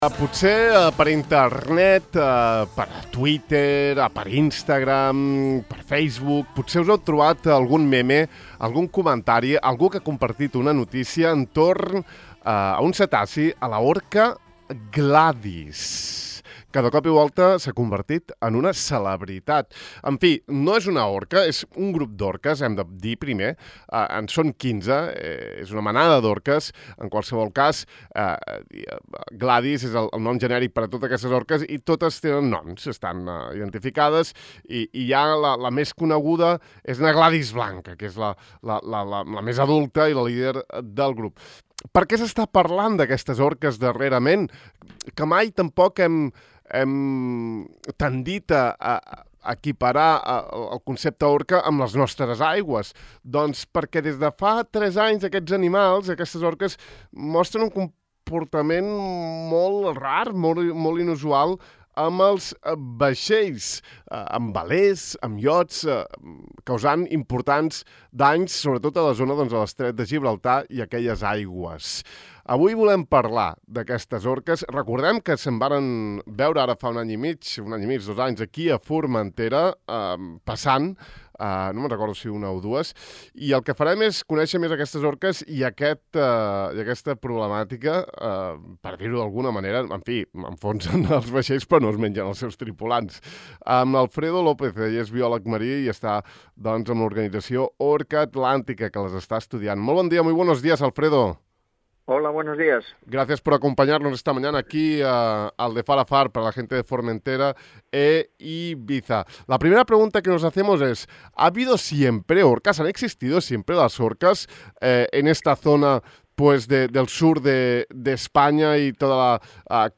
En l’entrevista ens explica tot el que cal saber de la presència d’aquest mític animal a les costes espanyoles, fins i tot ens parla del grup d’orques que fa tres anys va ser observat prop de la costa de Formentera.